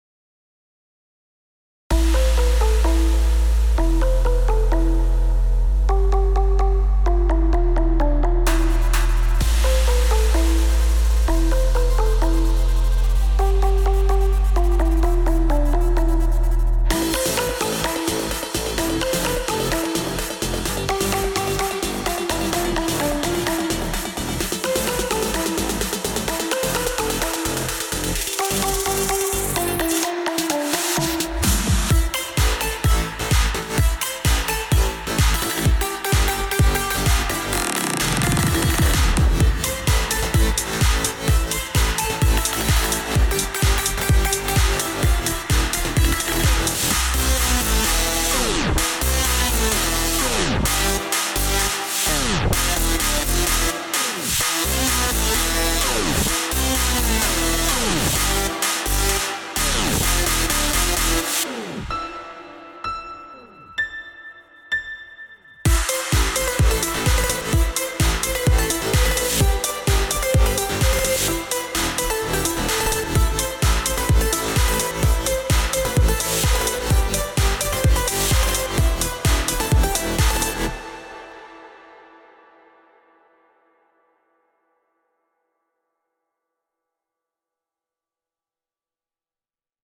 תוסיף עוד תדרים נמוכים.
וואו ממש יפה גם המיקס ואהבתי ממש את הפסנתר אולי הכנסה לפסנתר והיציאה חדים מידי ולדעתי צריך קצת נמוכים ל 2 תגובות תגובה אחרונה 0